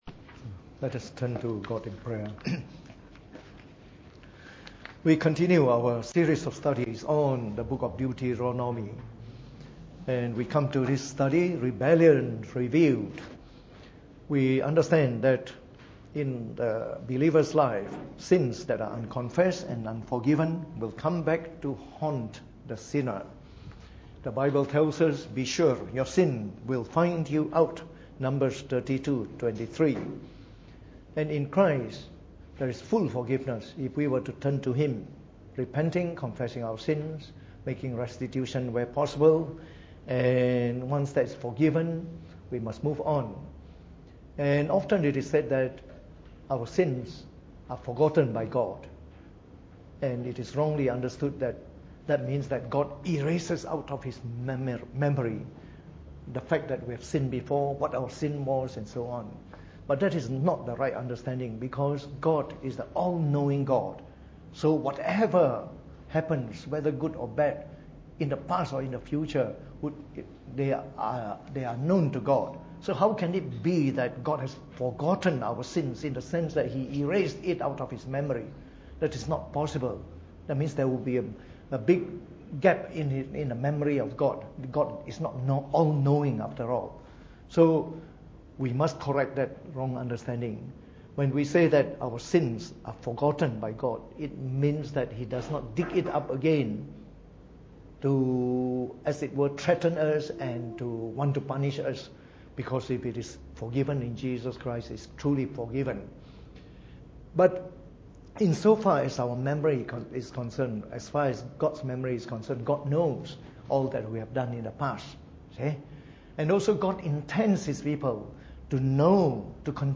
Preached on the 14th of March 2018 during the Bible Study, from our series on the book of Deuteronomy.